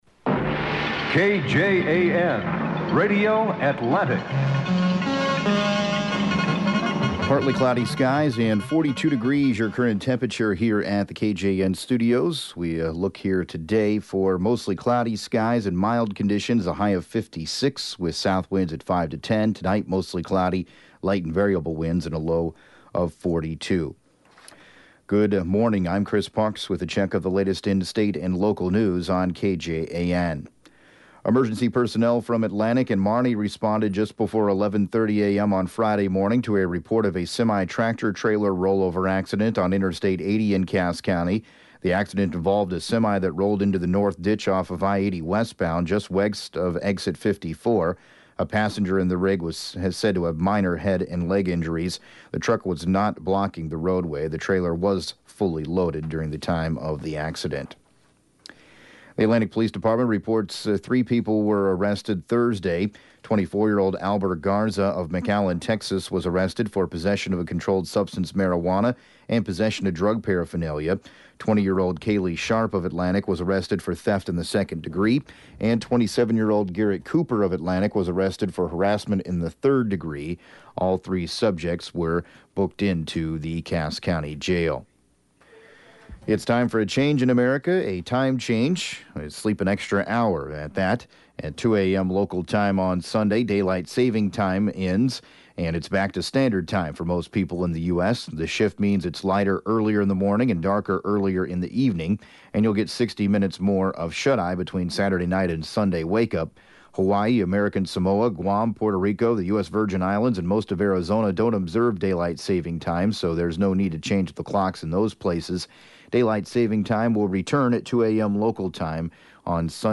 7AM Newscast 11/04/2017